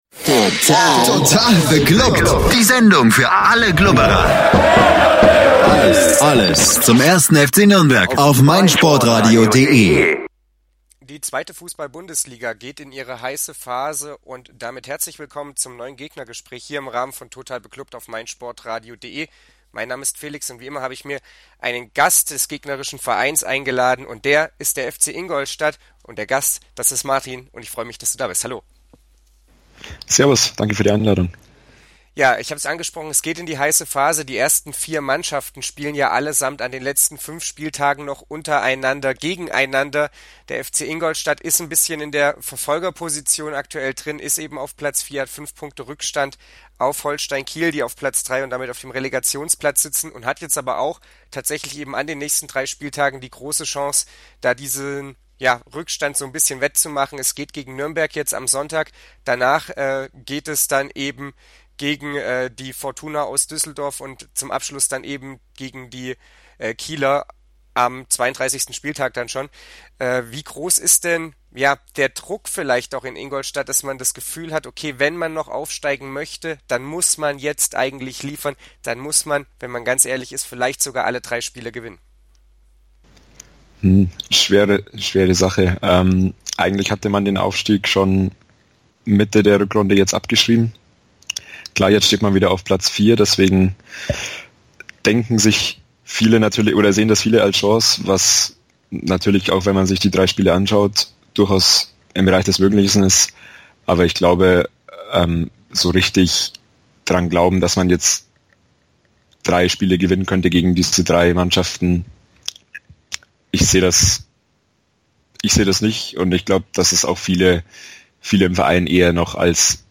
gegnergespraech-fc-ingolstadt-04.mp3